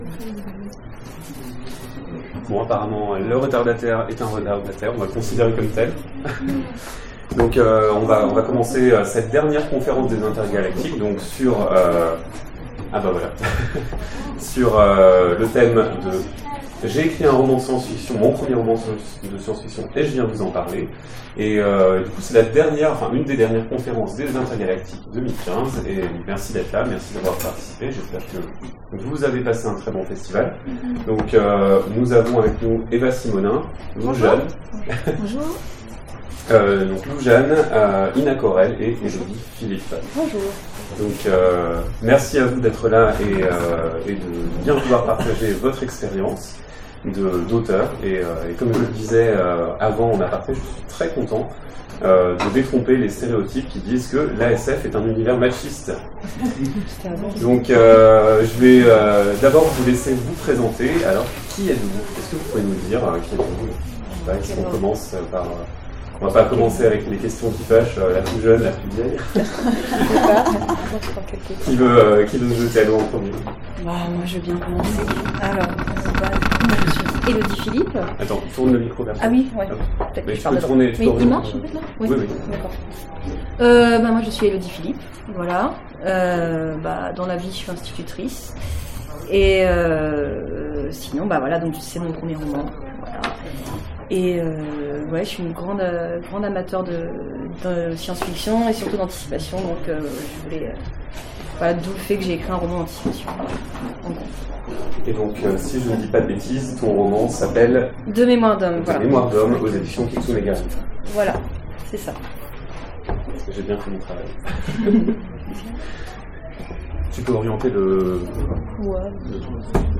Intergalactiques 2015 : Conférence J’ai écrit mon premier roman de Science-fiction